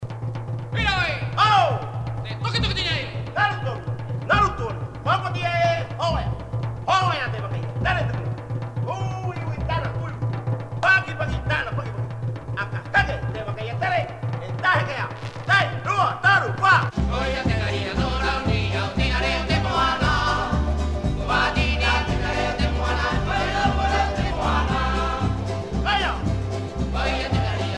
Groupe folklorique
Pièce musicale éditée